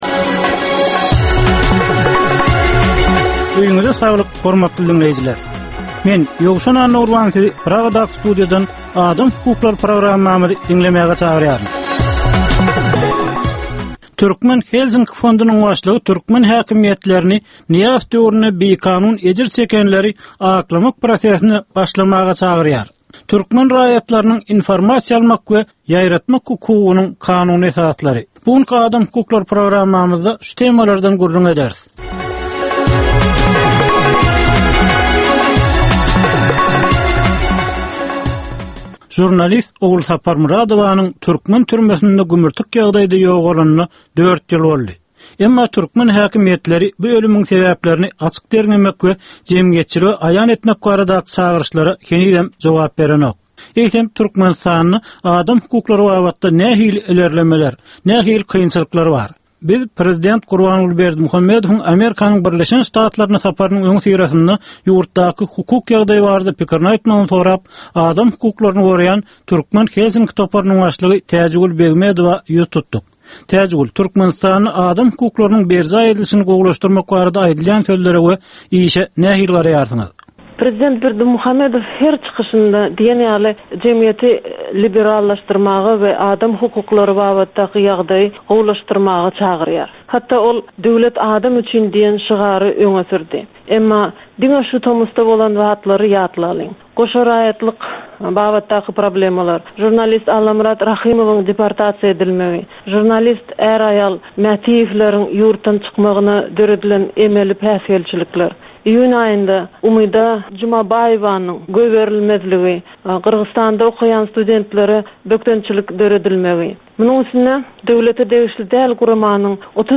Türkmenistandaky adam hukuklarynyň meseleleri barada ýörite programma. Bu programmada adam hukuklary bilen baglanyşykly anyk meselelere, problemalara, hadysalara we wakalara syn berilýär, söhbetdeşlikler we diskussiýalar gurnalýar.